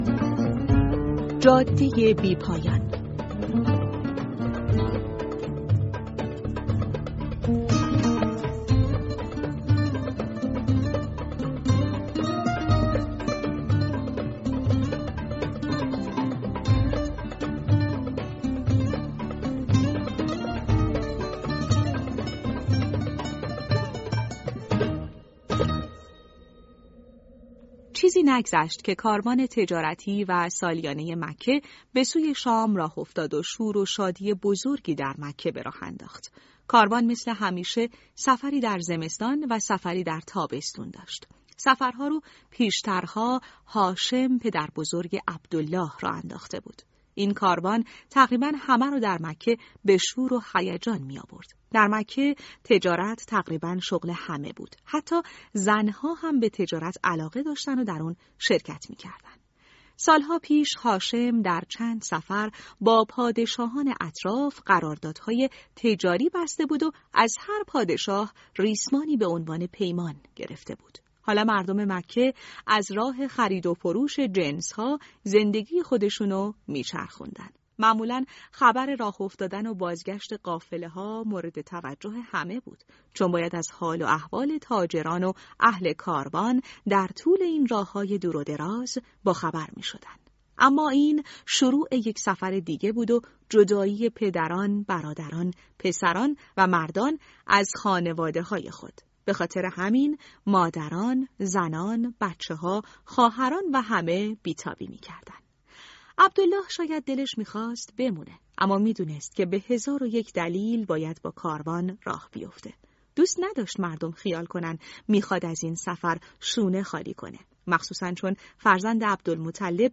دانلود صوت بفرمایید قصه کتاب صوتی «۳۶۶ روز با پیامبر عزیزمان» فصل پنجم راوی
# کتاب صوتی # روایتگری # یه صفحه کتاب # قصه کودک